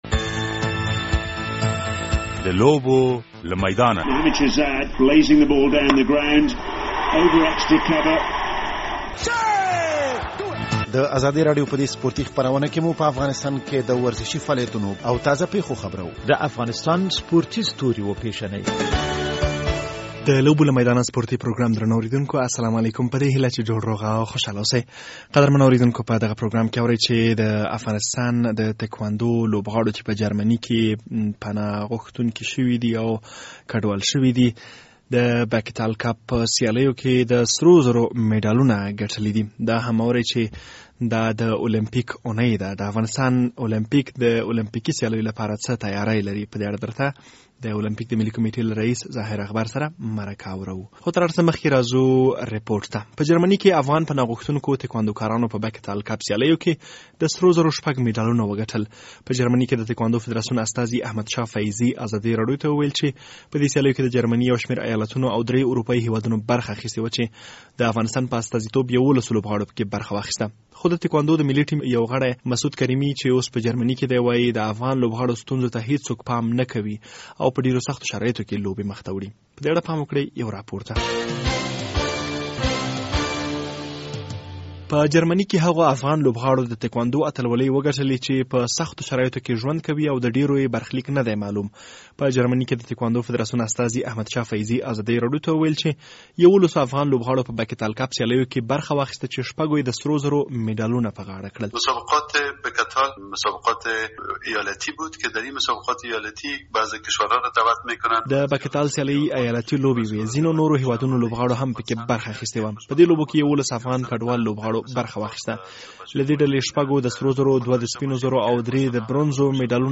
په دغه پروګرام کې د افغان تکواندو لوبغاړو د اتلوليو په اړه رپوټ اورئ او هم به له دې خبر شئ چې د المپيک په اوونۍ کې د المپيک ملي کمېټه څه تیارۍ لري.